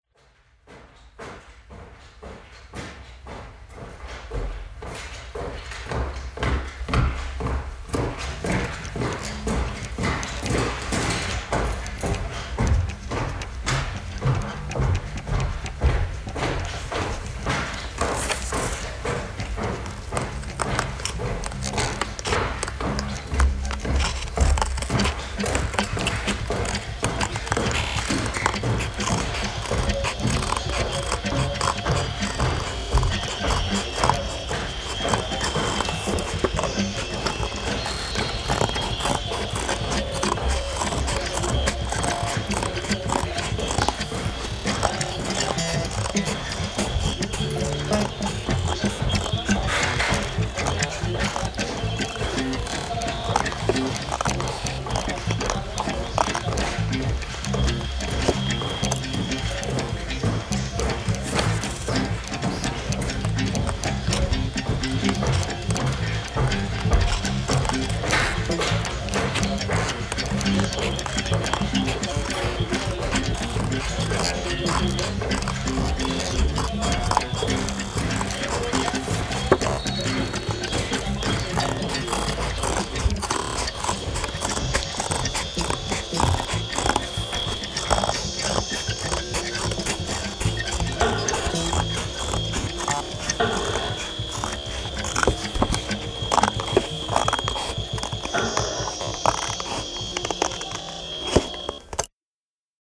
Genre: Contemporary Christian